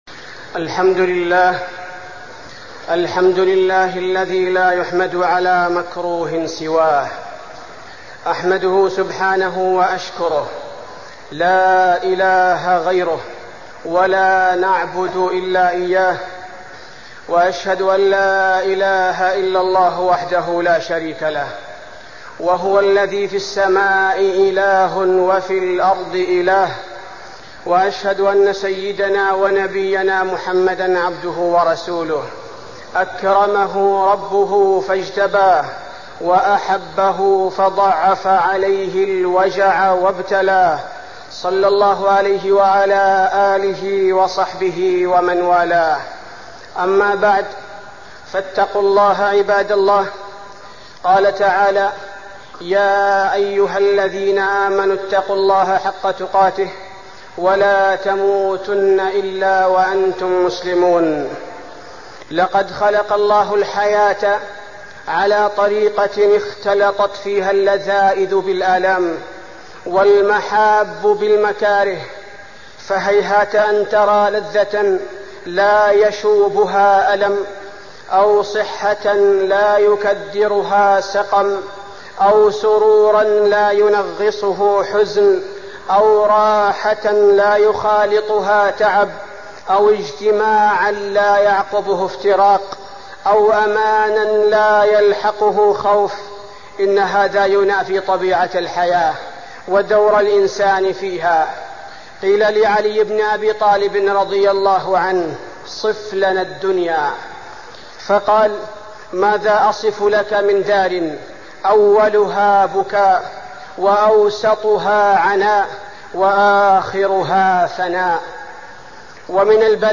تاريخ النشر ١٤ شعبان ١٤١٦ هـ المكان: المسجد النبوي الشيخ: فضيلة الشيخ عبدالباري الثبيتي فضيلة الشيخ عبدالباري الثبيتي الصبر على الابتلاء